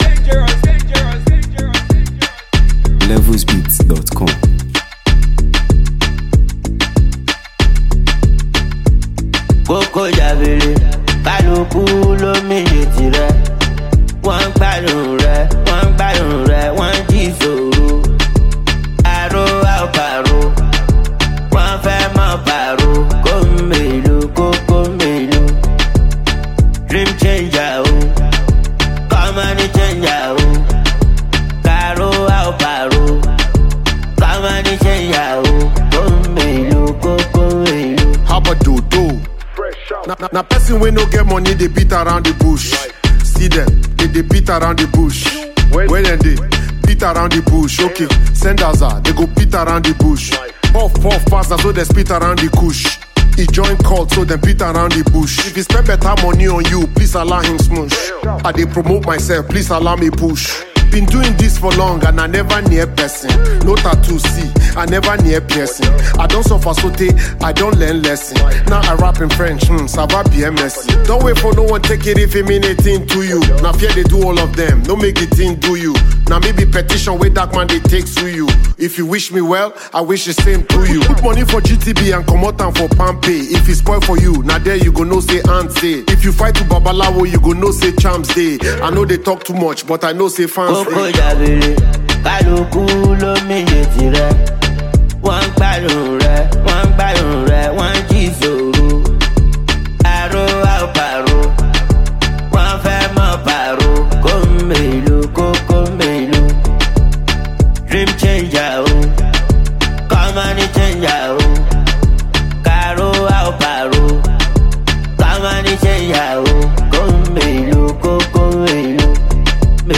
On this energetic track